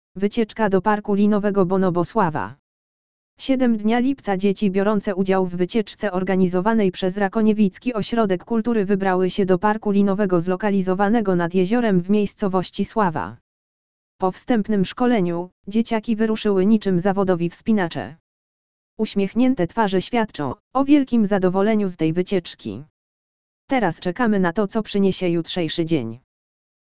lektor_audio_wycieczka_do_parku_linowego_bonobo_slawa.mp3